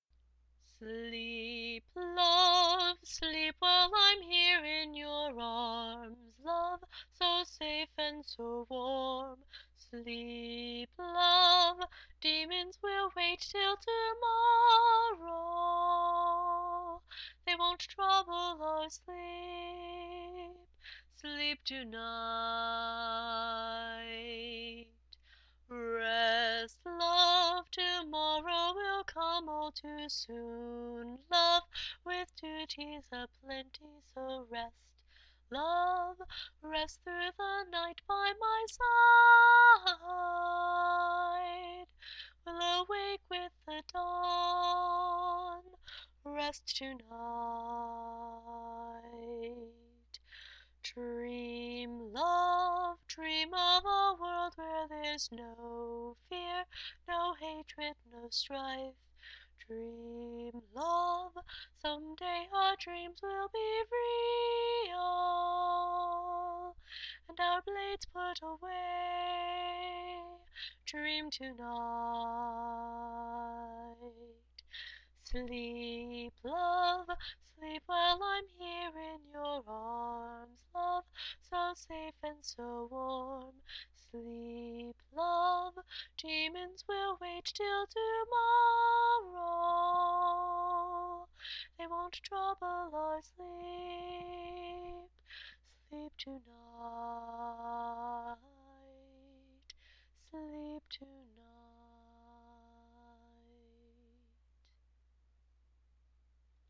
This was originally a lullaby from one character to another, but it is so universally appropriate that it entered my more standard repertoire.